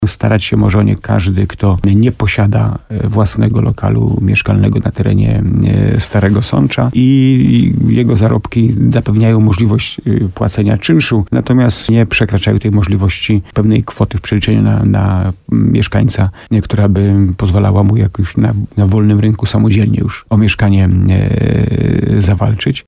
– Chodzi o to, że będzie trzeba już tylko je umeblować – mówi burmistrz Starego Sącza, Jacek Lelek.